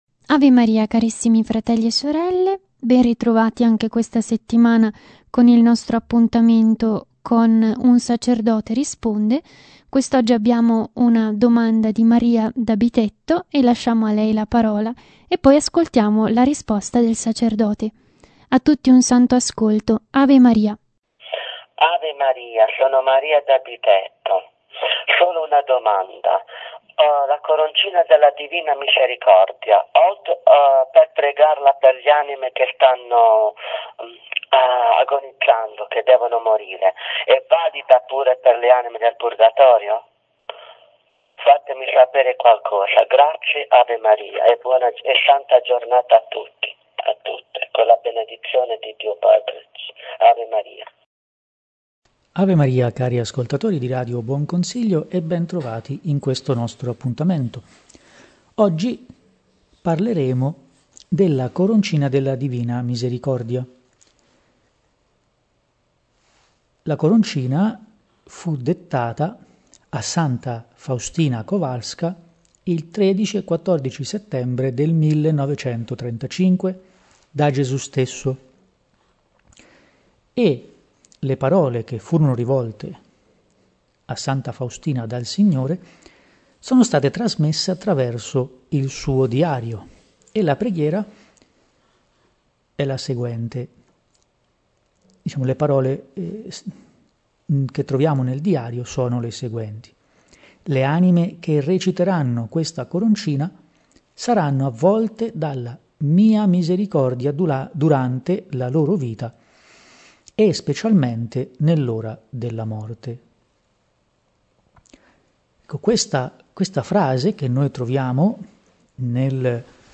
Genere: Un sacerdote risponde.